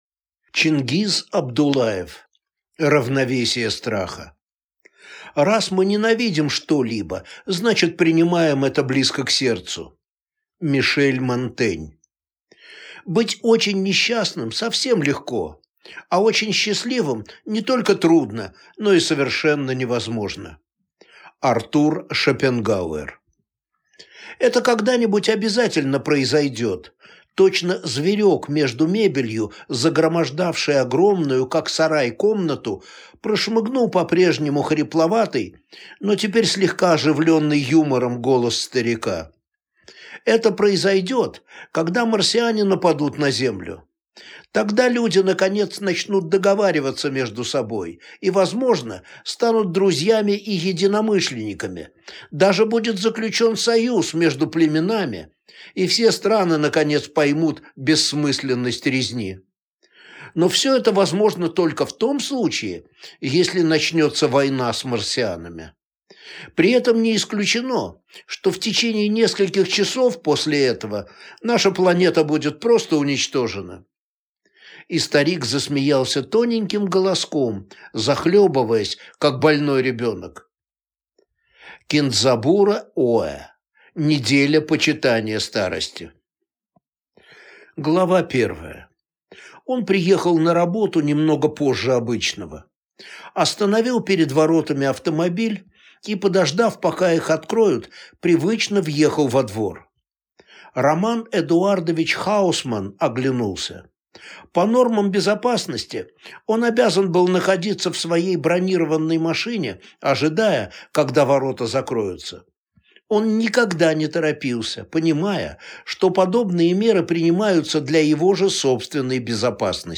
Аудиокнига Равновесие страха | Библиотека аудиокниг